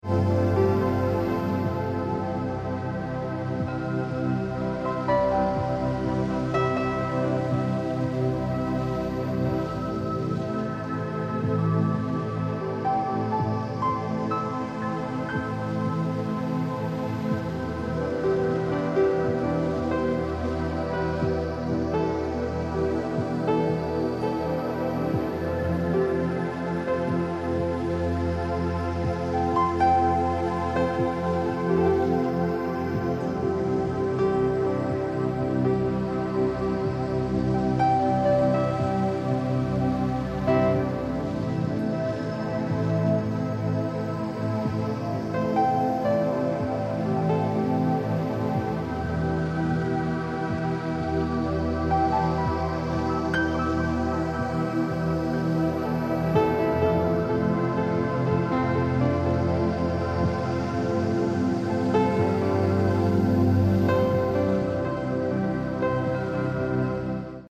Reiner Klang   15:13 min